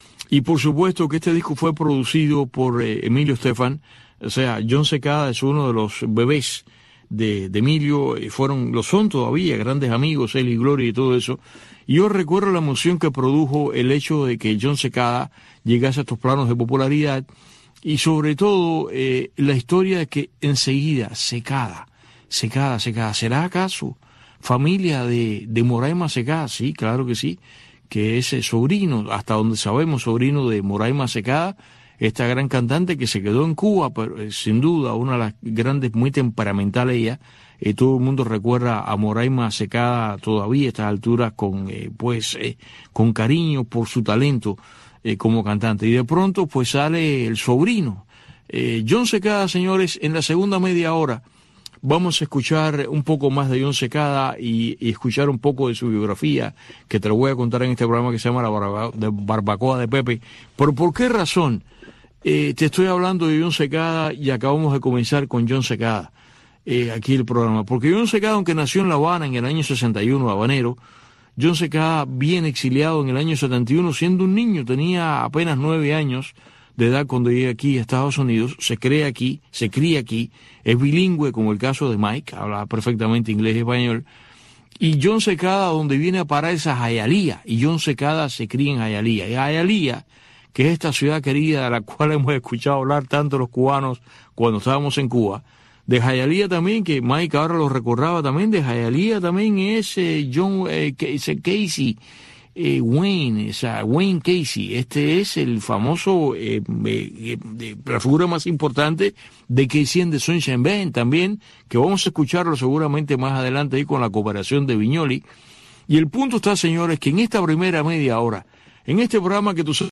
en este espacio informativo en vivo, que marca el paso al acontecer cubano